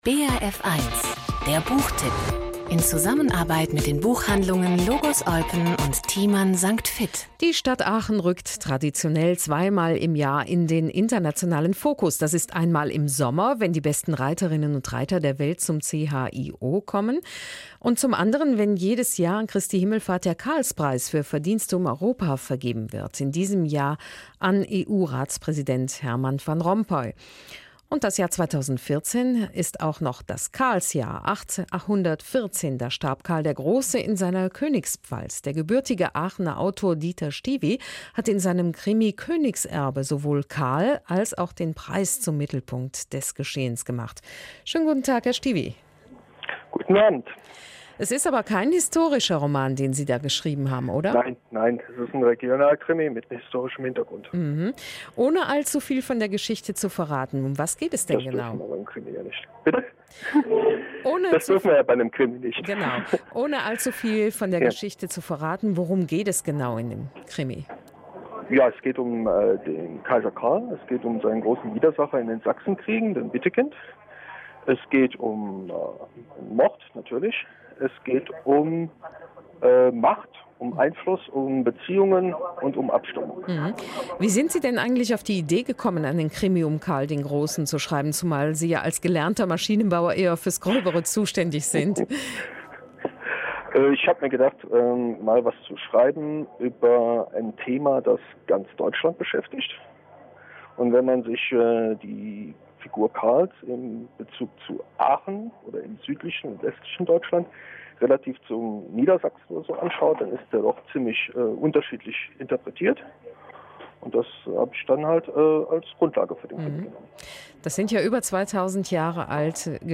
Belgischer Rundfunk 24.04.2014, Interview